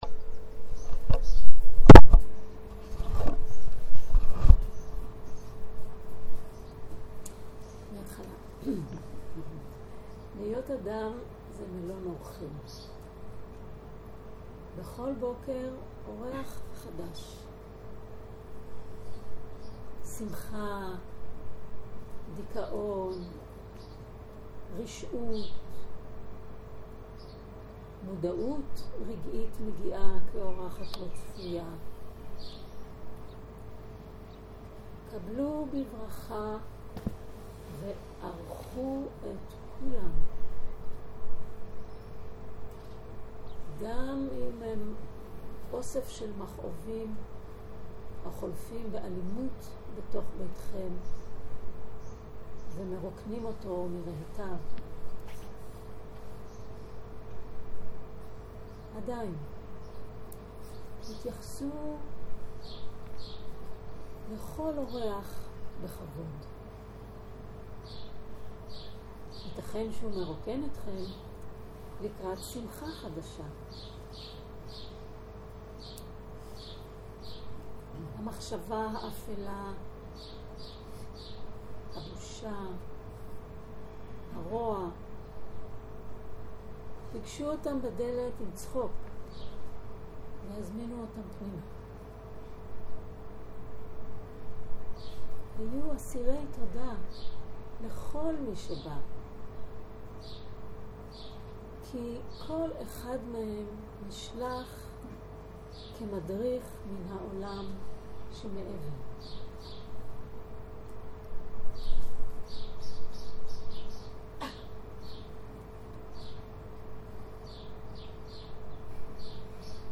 שיחת הנחיות למדיטציה שפת ההקלטה